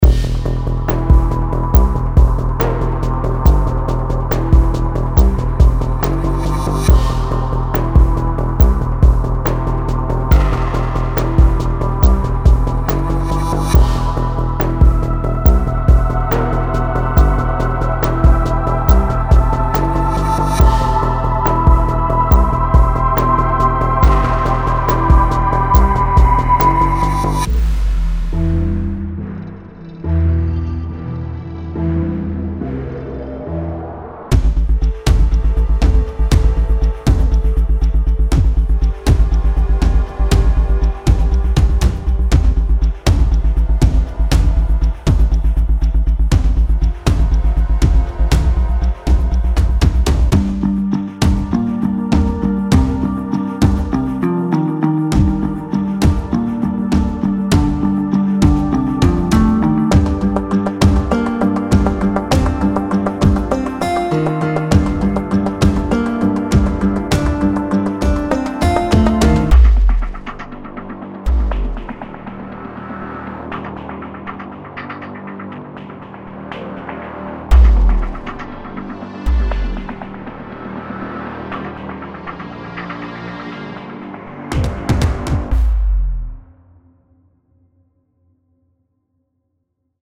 优美的旋律循环，戏剧性的序列
奏低音，史诗般的弦，预告片启发的音效，独特的鼓和打击乐循环等等。